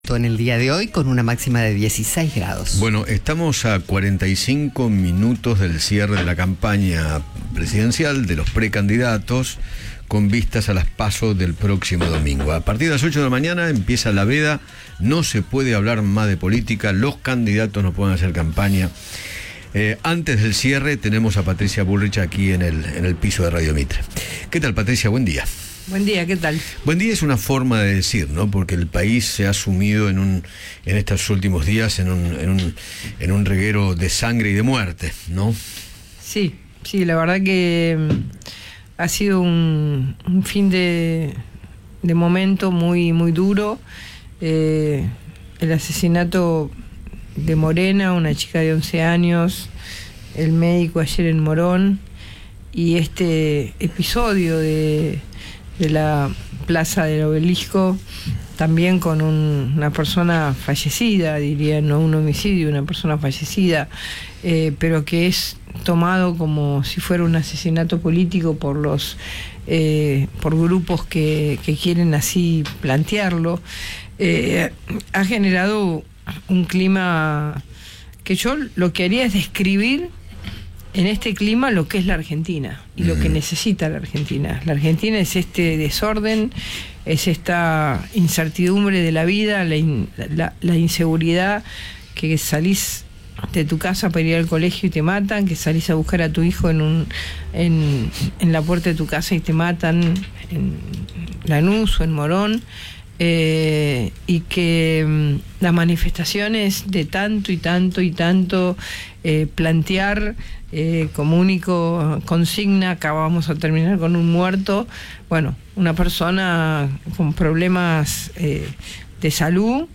Patricia Bullrich, precandidata a presidente por Juntos por el Cambio, conversó con Eduardo Feinmann, en la previa al inicio de la veda electoral, y manifestó los motivos por los que la deben elegir dentro de la interna.